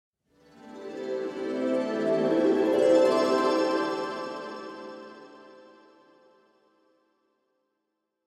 Flutter Harp.wav